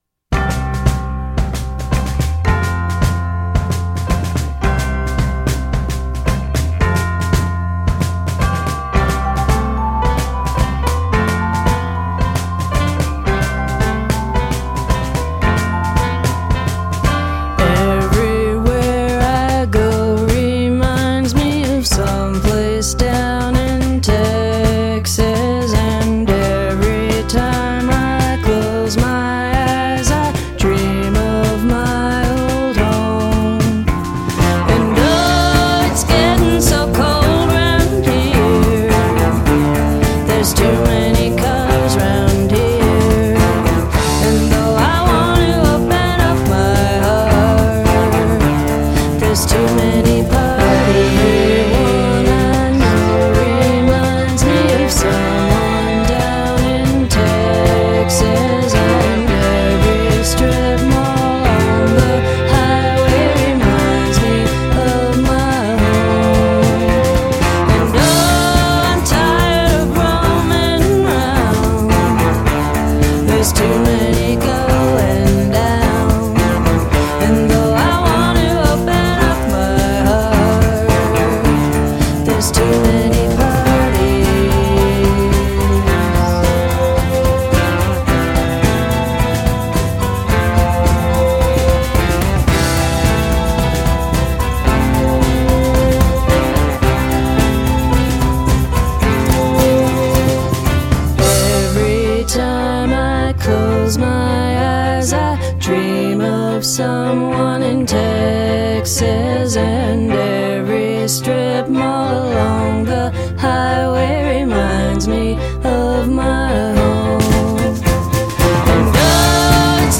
pensive countrified psychedelia